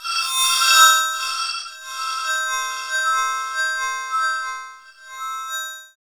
SI2 CHIME07R.wav